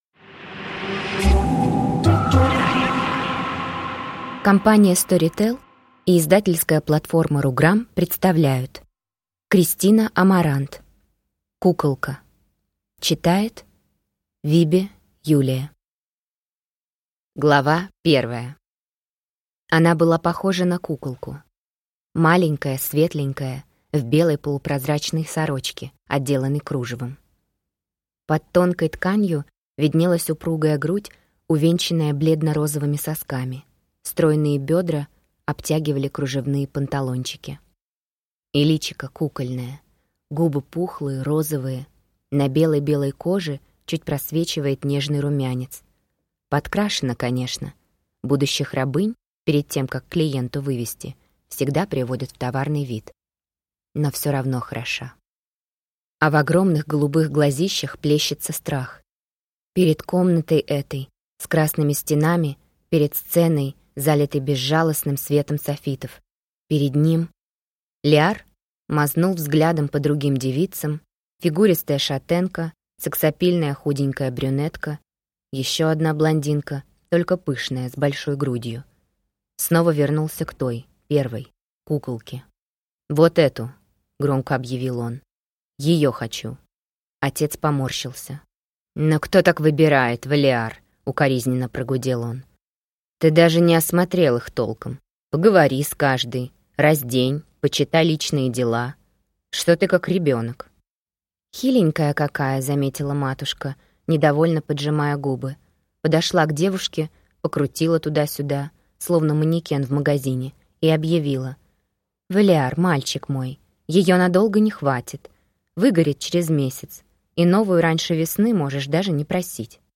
Аудиокнига Куколка | Библиотека аудиокниг